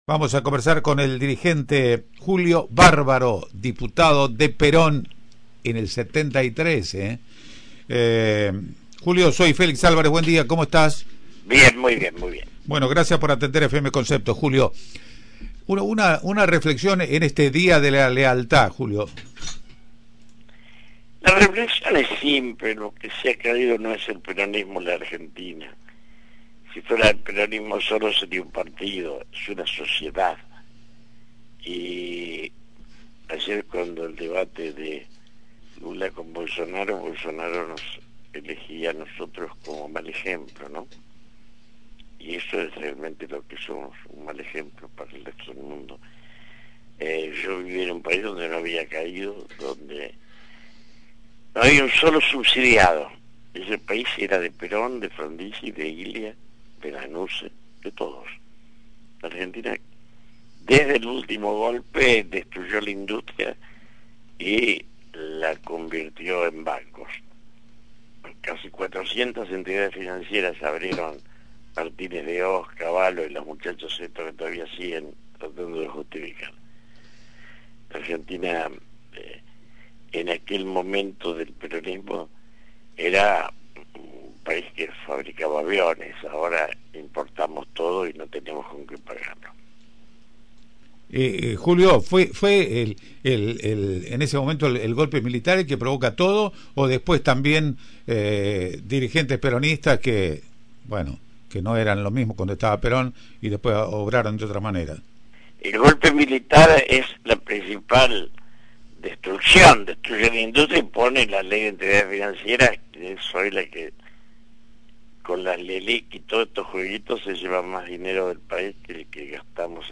El dirigente Julio Bárbaro dialogó con el periodista